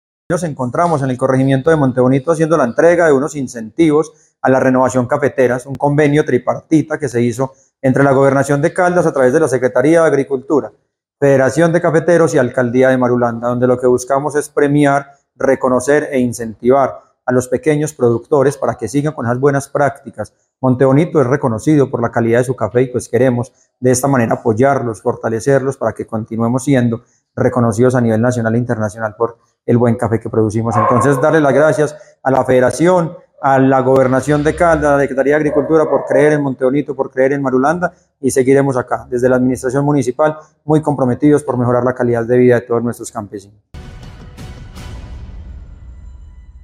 Leonardo Giraldo Botero, alcalde de Marulanda.